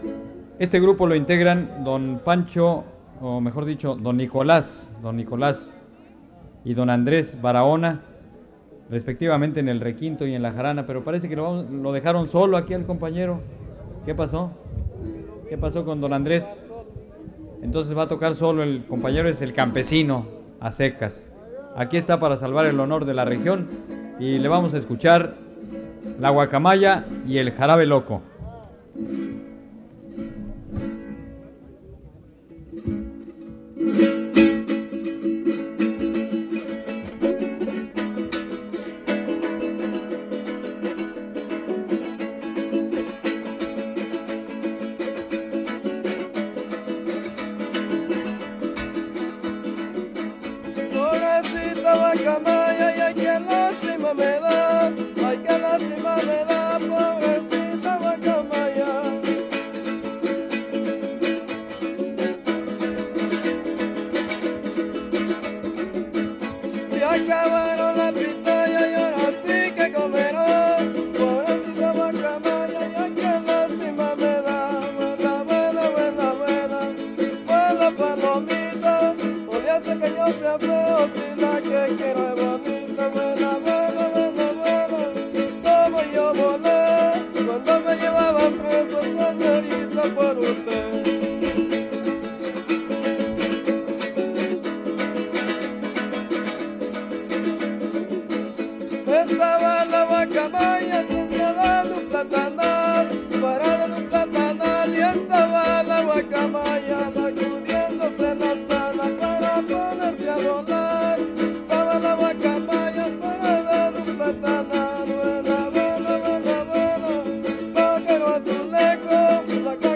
• Los campesinos (Grupo musical)
Noveno Encuentro de jaraneros